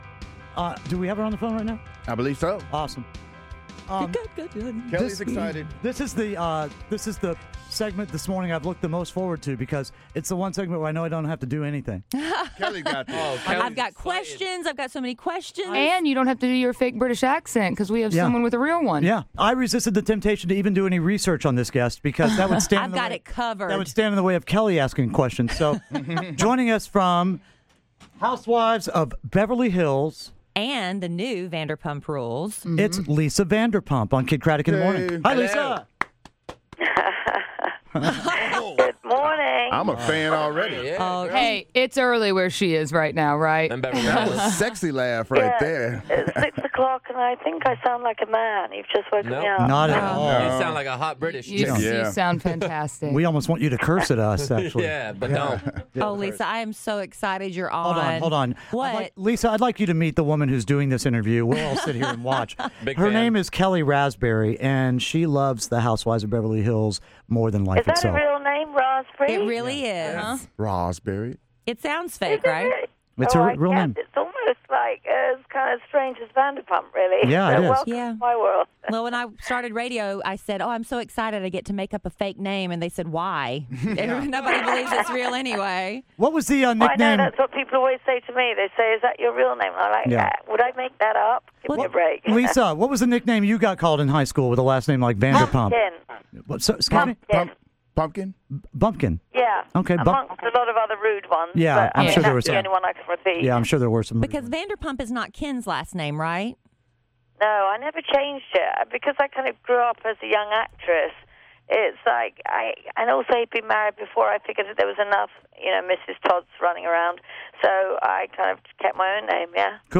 Lisa Vanderpump Interview
Lisa Vanderpump from Real Housewives of Beverly Hills calls the show